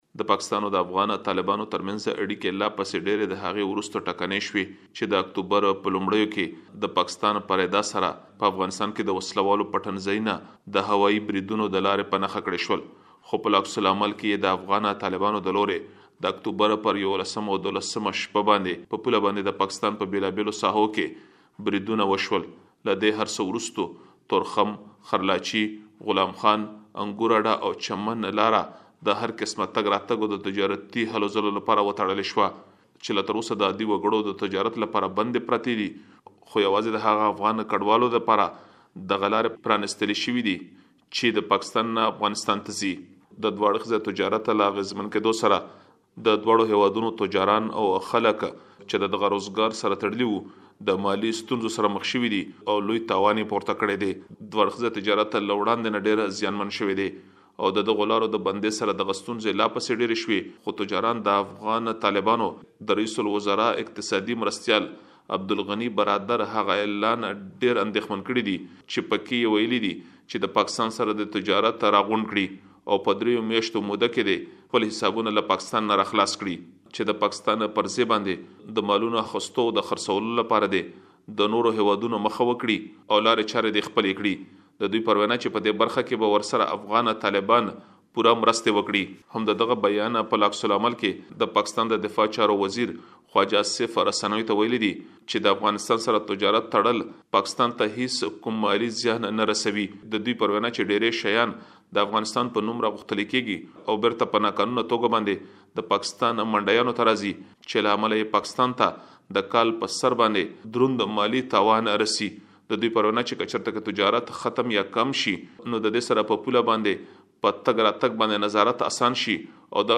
مهرباني وکړئ لا ډېر معلومات دلته په رپوټ کې واورئ.